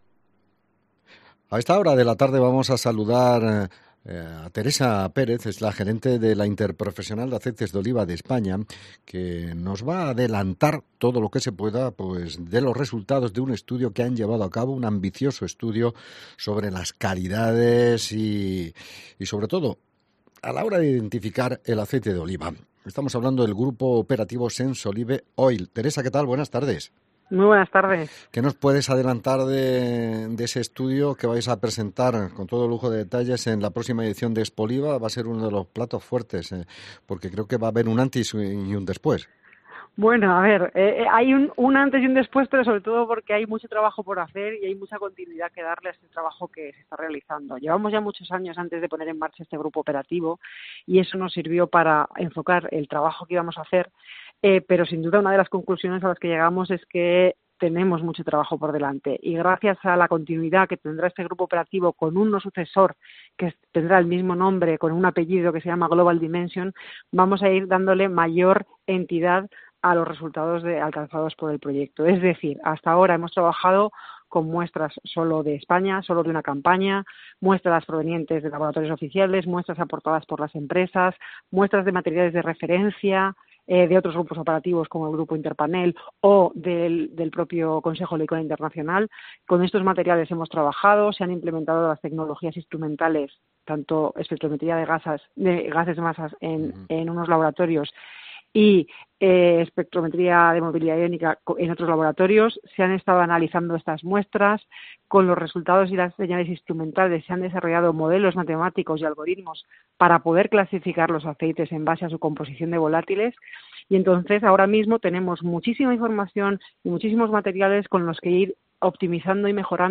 En Directo COPE JAÉN